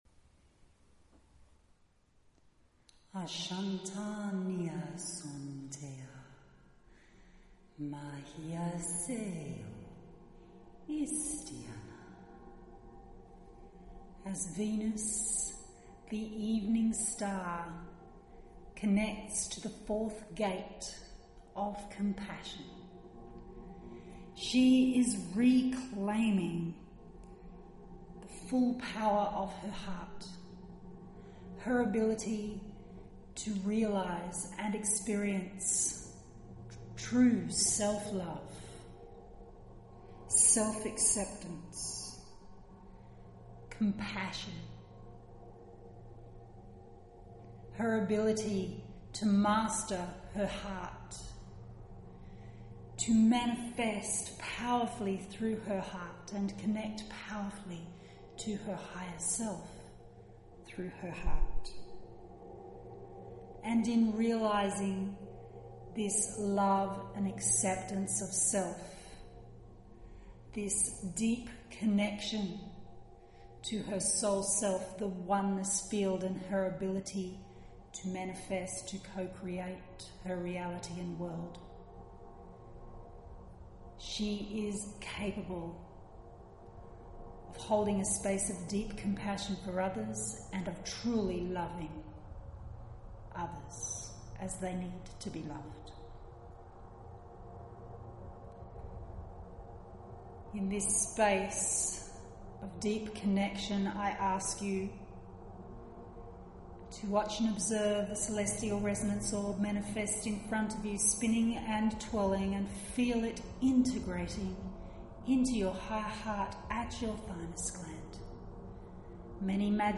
Here is a SNEAK PEEK of this AMAZING MEDITATION/TRANSMISSION – sharing the first 2 minutes of the 13 minute Celestial Resonance Meditation Journey, you will need to purchase the mp3 audio file to experience the full journey.